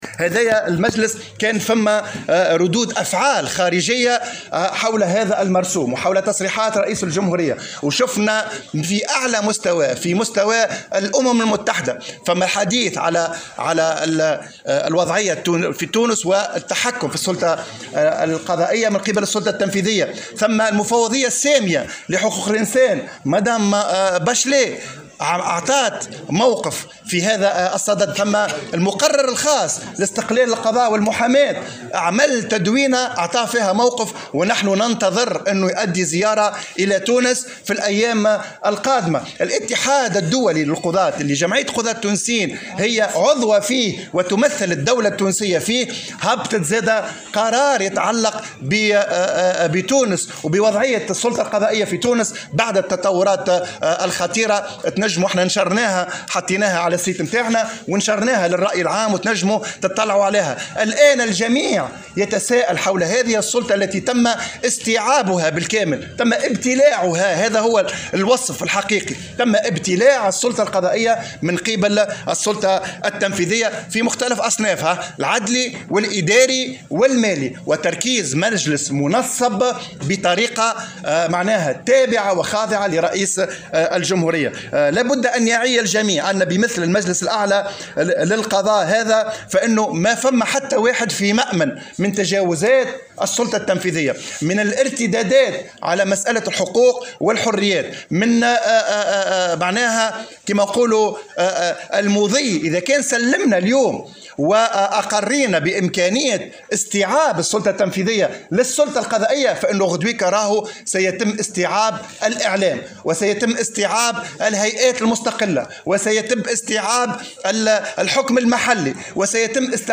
ندوة صحفية